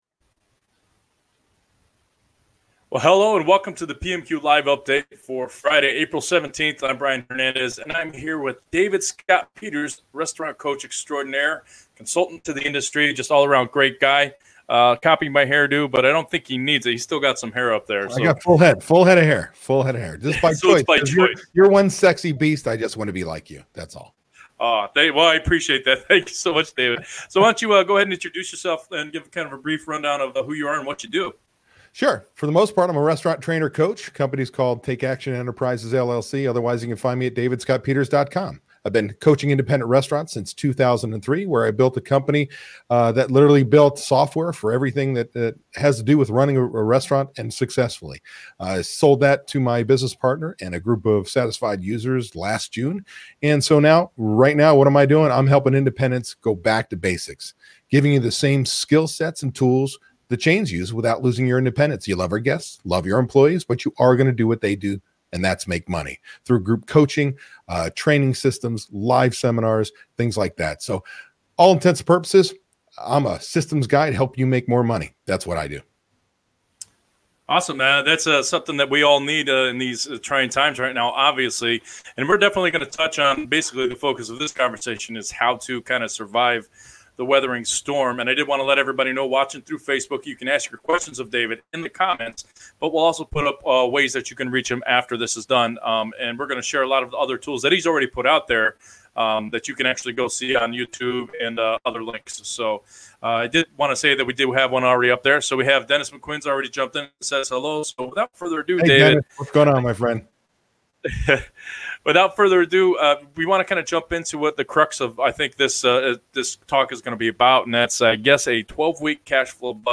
Join us for a discussion